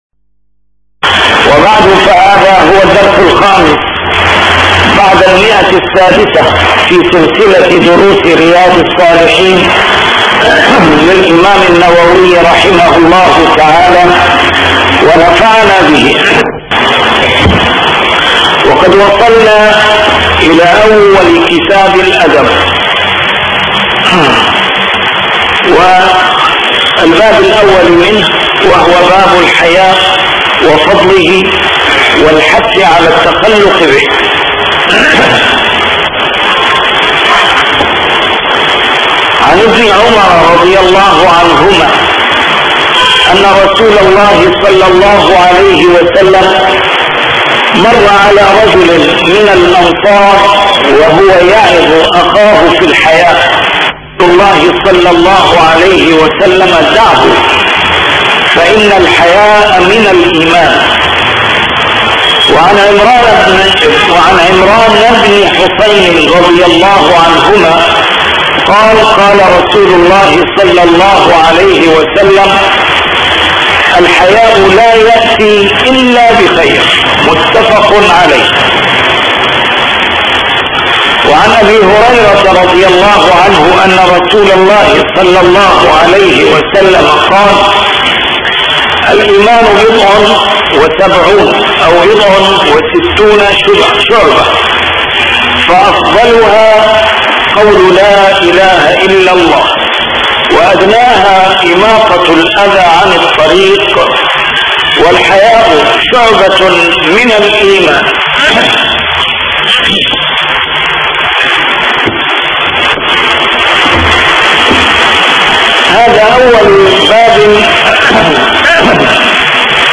A MARTYR SCHOLAR: IMAM MUHAMMAD SAEED RAMADAN AL-BOUTI - الدروس العلمية - شرح كتاب رياض الصالحين - 605- شرح رياض الصالحين: الحياء